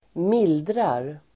Uttal: [²m'il:drar]